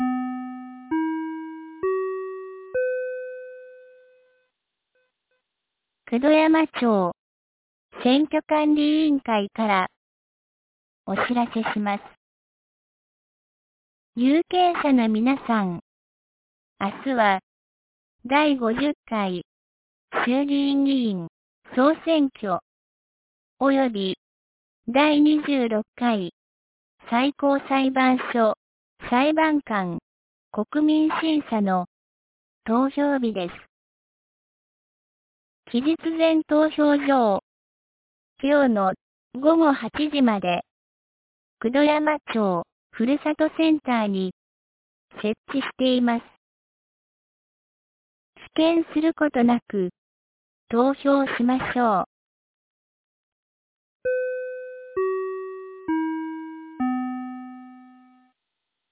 2024年10月26日 12時31分に、九度山町より全地区へ放送がありました。